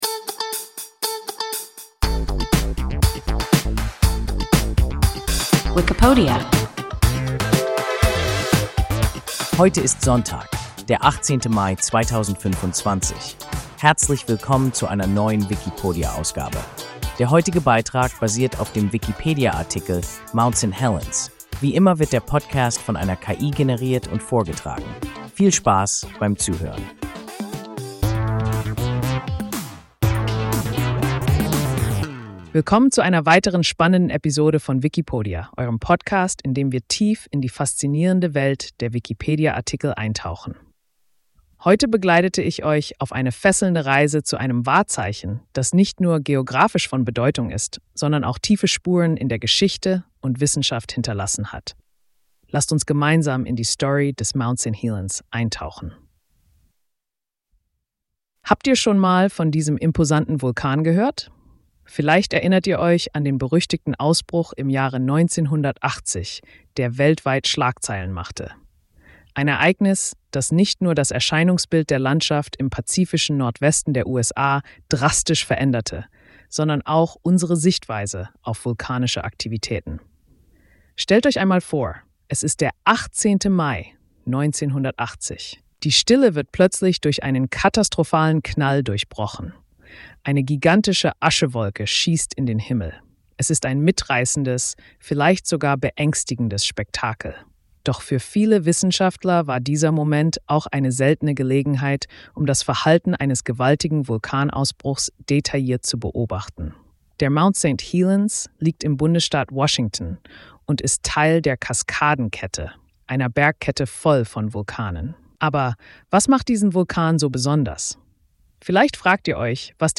Mount St. Helens – WIKIPODIA – ein KI Podcast